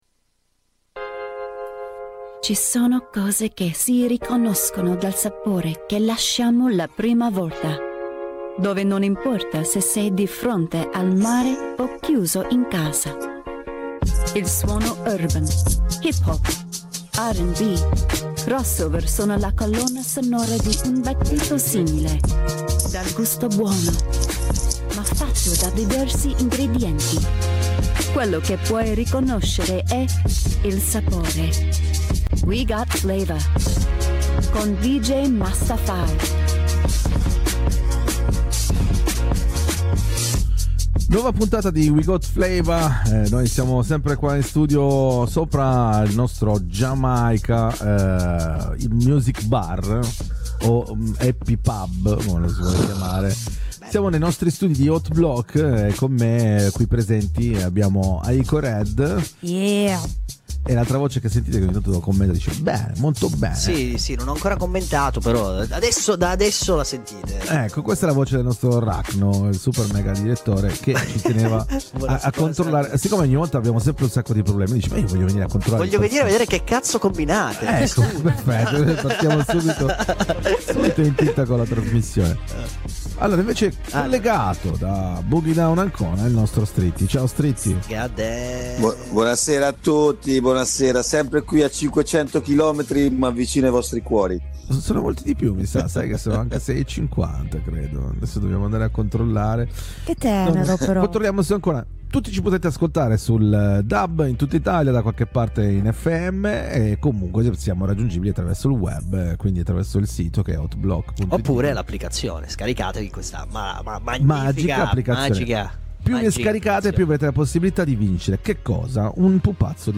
La chiacchierata